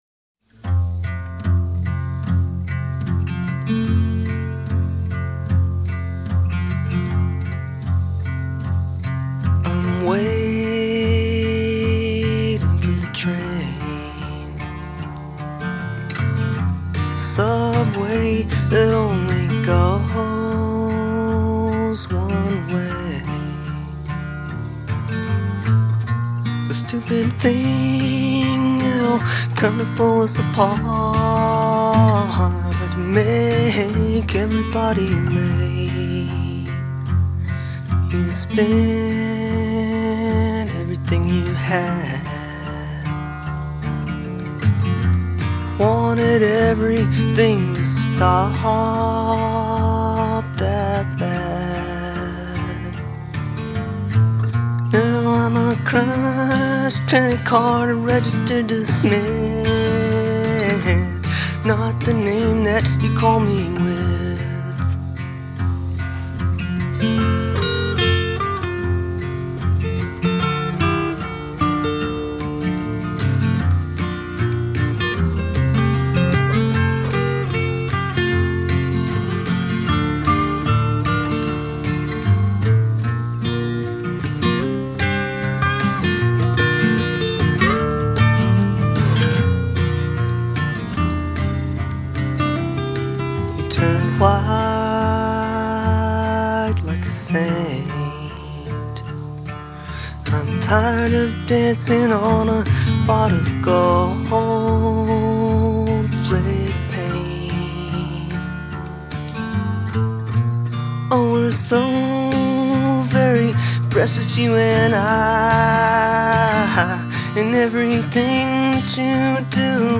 Genre: Indie Rock / Singer-Songwriter / Acoustic